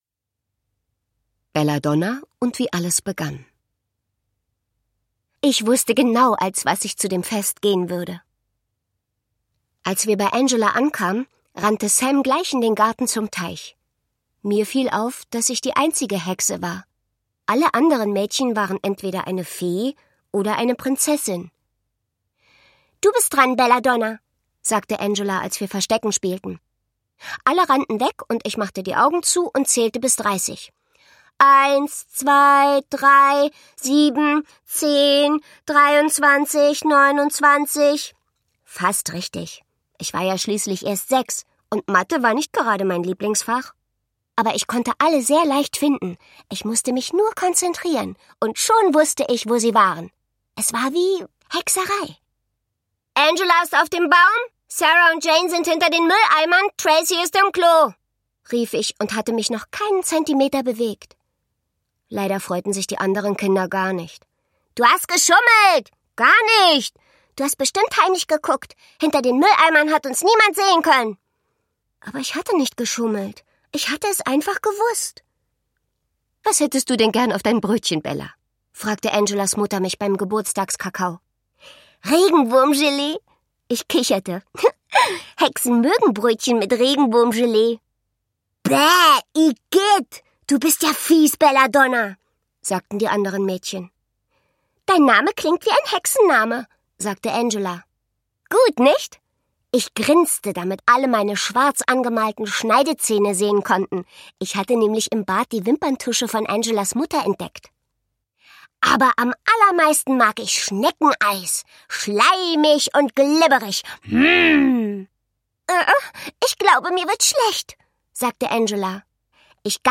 • Hexe; Kinder-/Jugendliteratur • Hörbuch; Lesung für Kinder/Jugendliche • Kinderheim • Mädchen • Serie • Zaubern